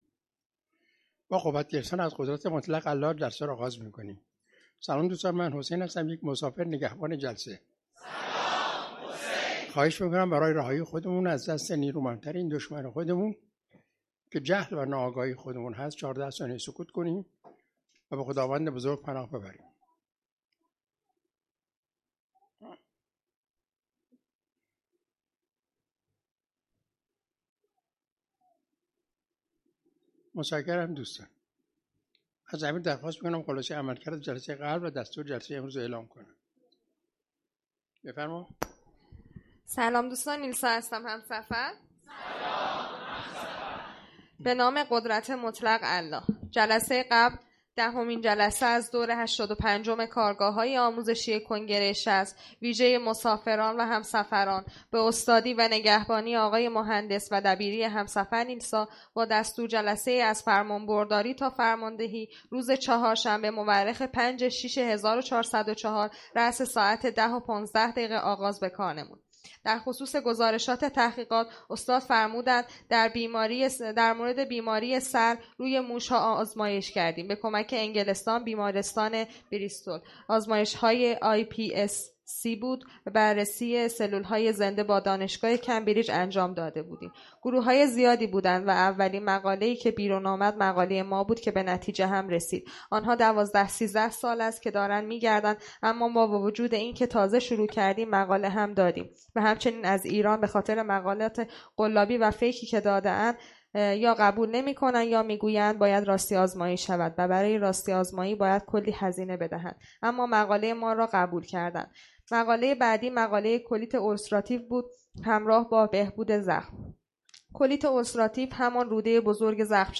کارگاه آموزشی جهان‌بینی؛وادی هفتم و تاثیر آن روی من